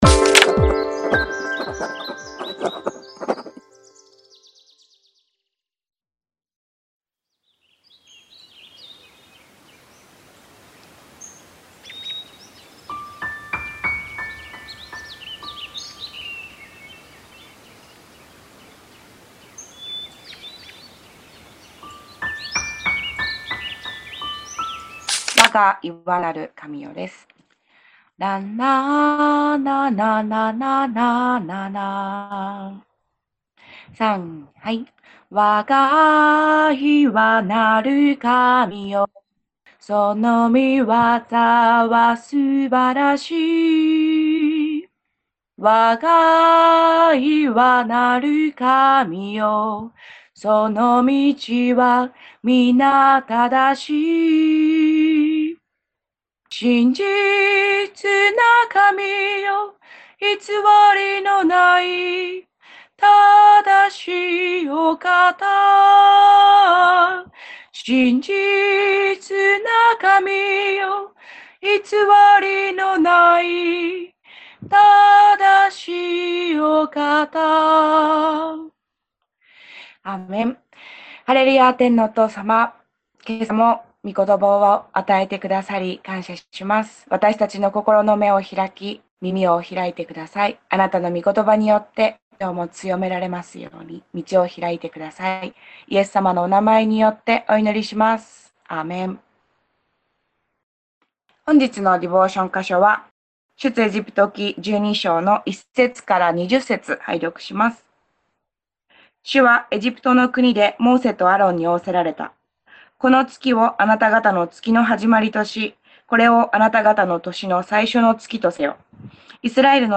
※映像と音声が一部乱れている部分がございます。